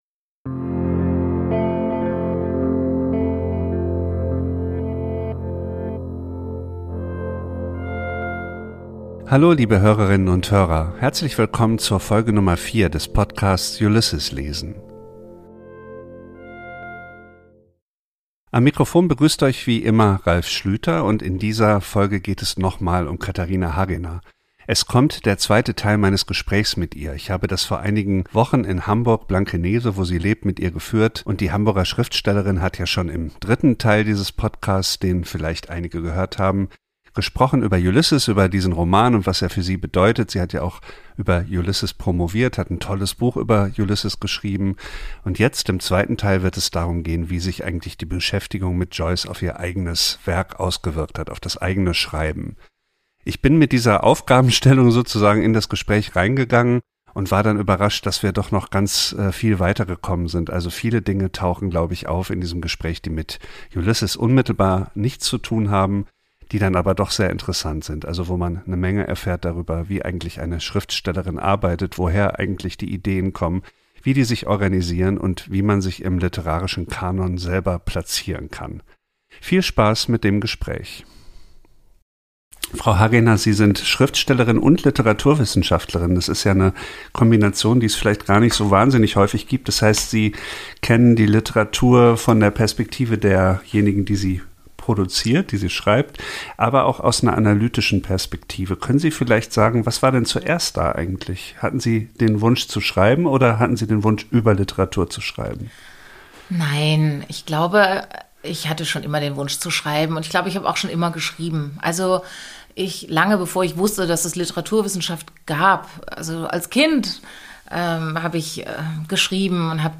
Beschreibung vor 4 Jahren Im zweiten Teil des Gesprächs geht es um den Einfluss von Joyce und »Ulysses« auf Hagenas Arbeit: Wie viele Verweise verträgt ein Roman?